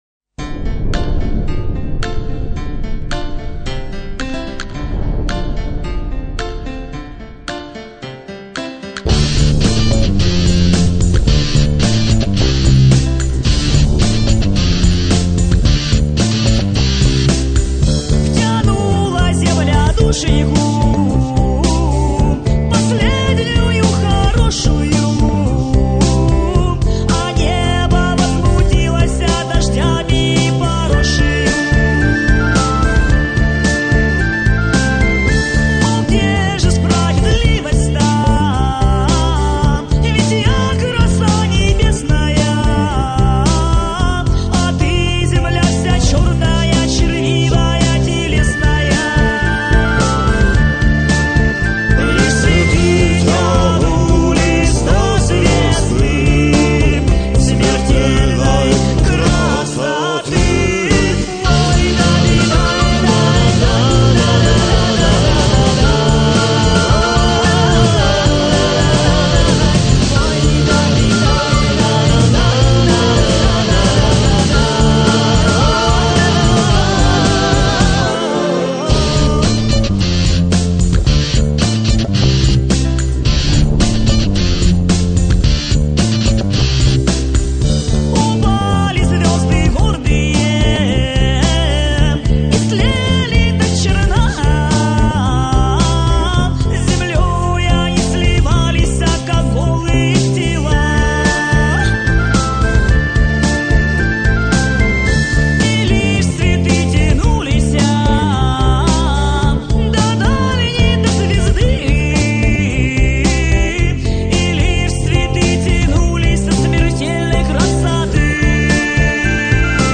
sskiy_folk_neizvestnyy_ispolnitel.mp3